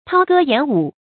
韜戈偃武 注音： ㄊㄠ ㄍㄜ ㄧㄢˇ ㄨˇ 讀音讀法： 意思解釋： 收藏兵器，停止武備。謂息兵修文。